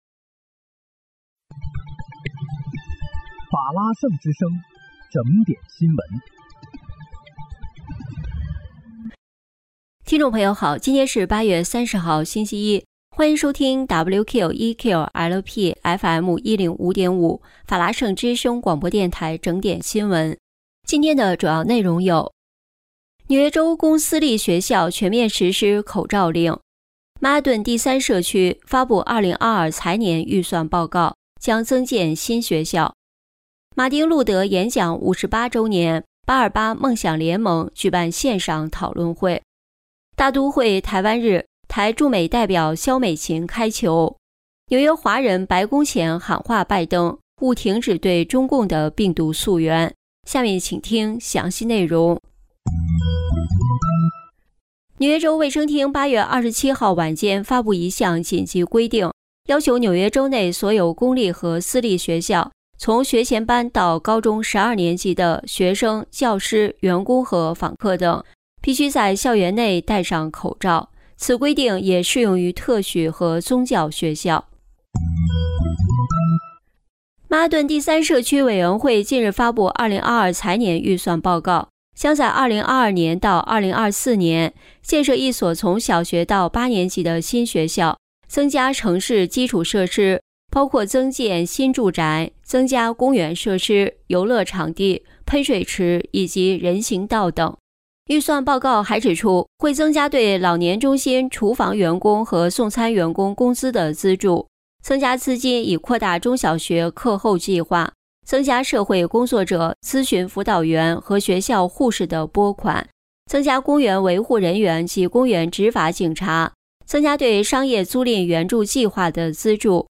8月30日（星期一）纽约整点新闻